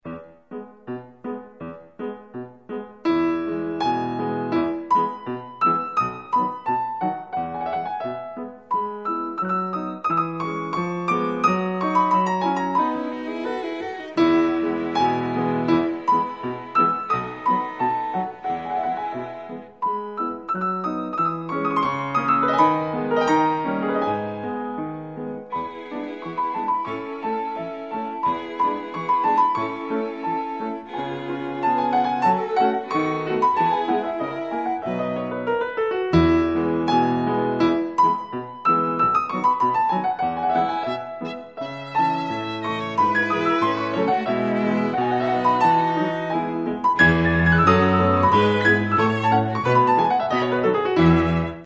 Piano Trio No.5 (III. Rondo
piano
violin
celo